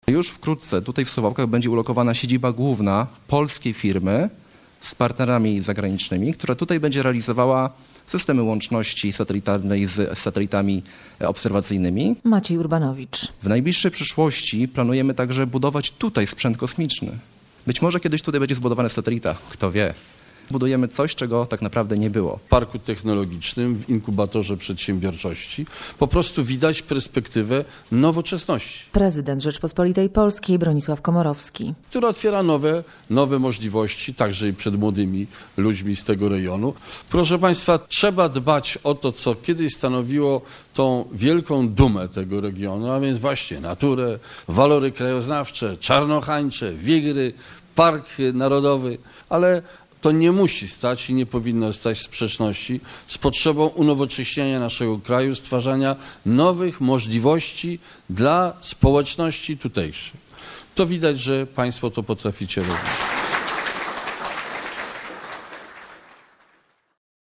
Bronisław Komorowski w Parku Naukowo-Technologicznym w Suwałkach - relacja